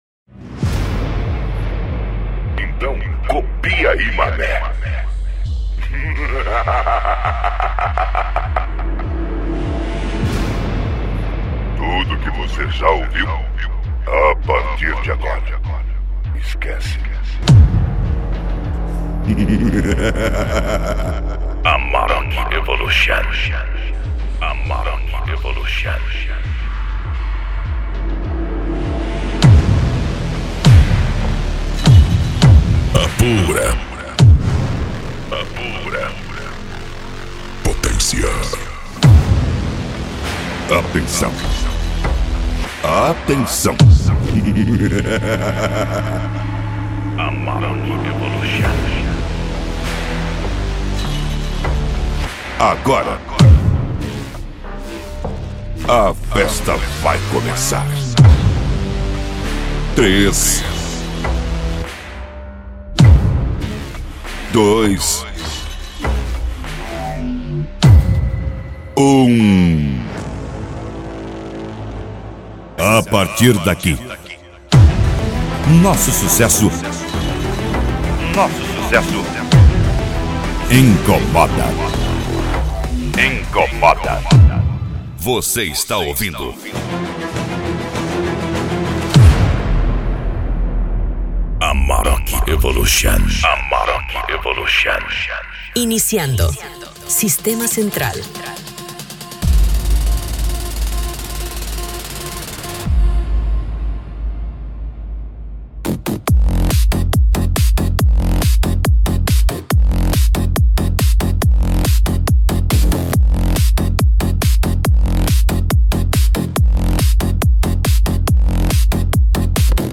Funk
Remix